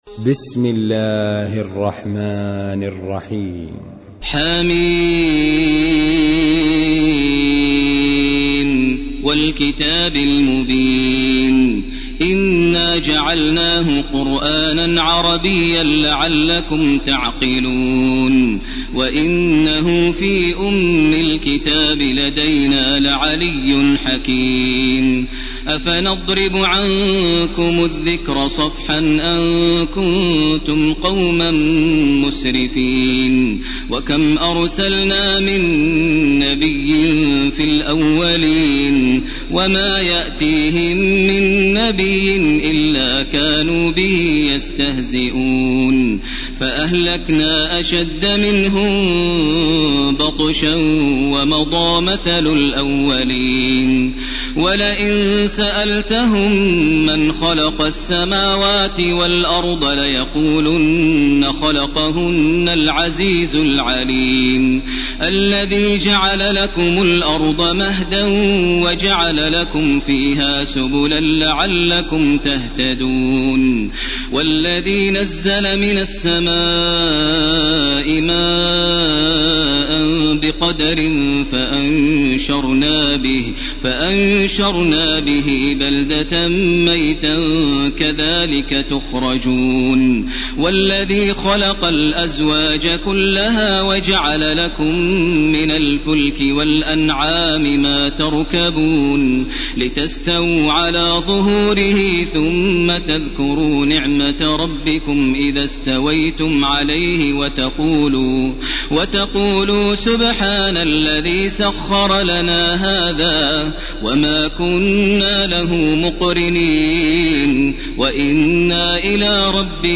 تراويح ليلة 24 رمضان 1431هـ سورتي الزخرف و الدخان Taraweeh 24 st night Ramadan 1431H from Surah Az-Zukhruf to Ad-Dukhaan > تراويح الحرم المكي عام 1431 🕋 > التراويح - تلاوات الحرمين